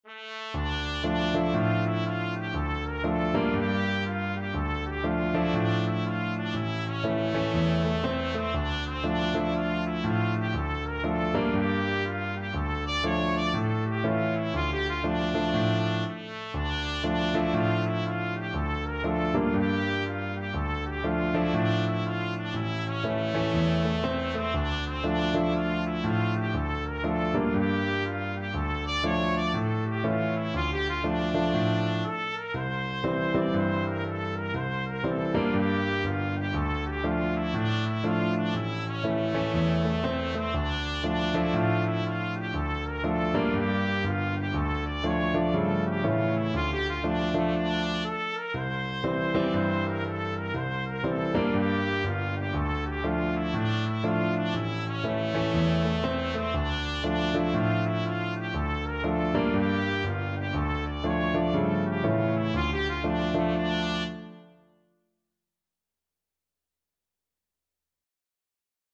2/2 (View more 2/2 Music)
A4-D6
With a swing = c.60
Traditional (View more Traditional Trumpet Music)